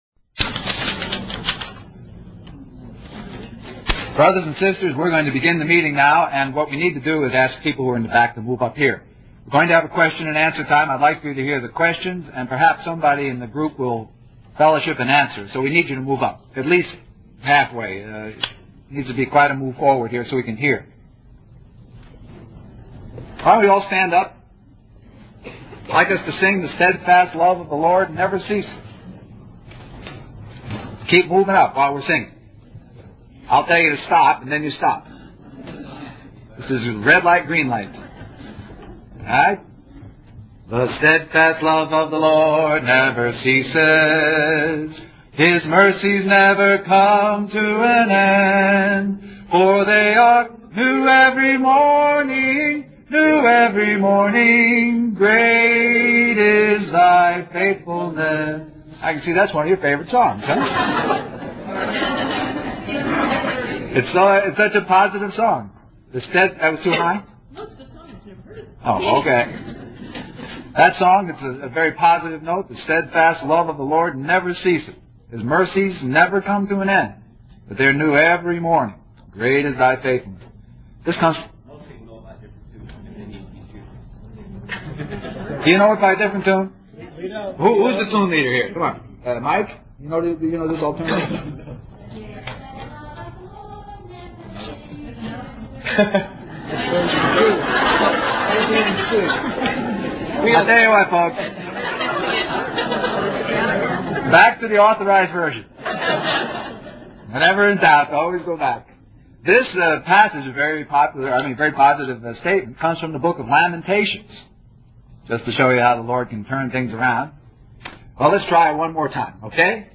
Christian Family Conference
The meeting concludes with a time for questions and answers, addressing various issues related to depression and the Christian walk.